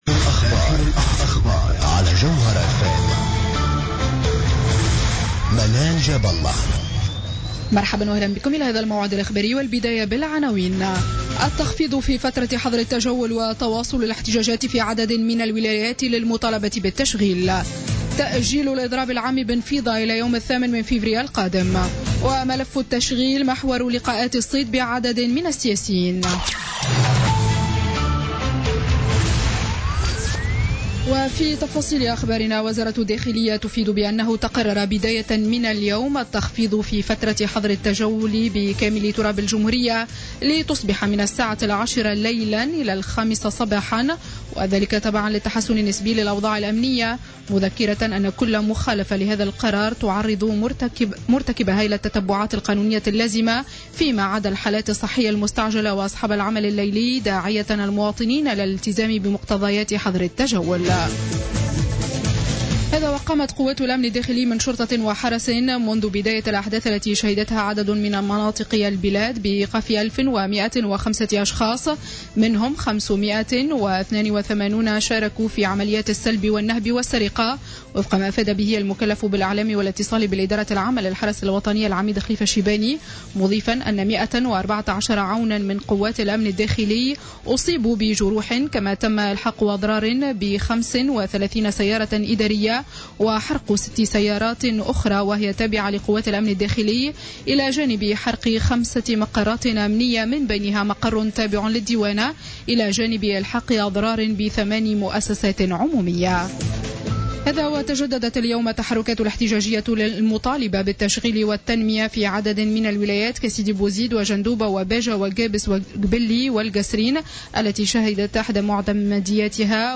Journal Info 19h00 du lundi 25 janvier 2016